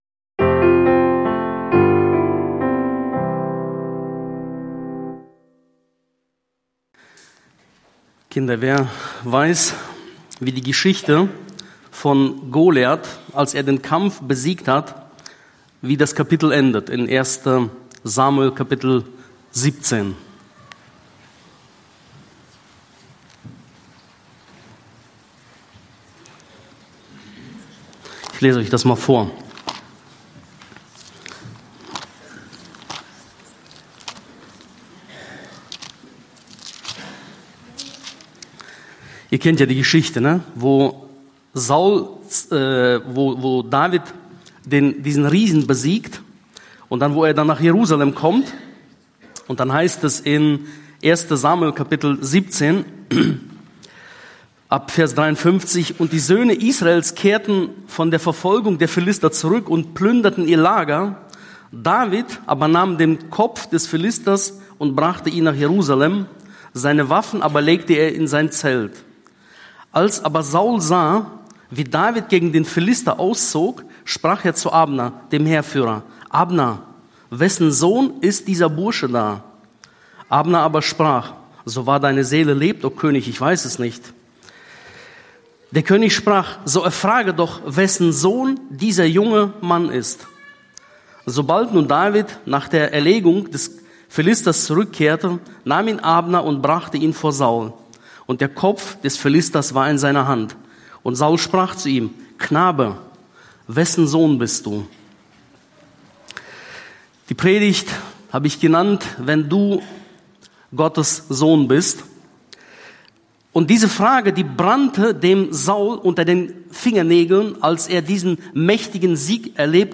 Predigt am Karfreitag über Jesus und seine Macht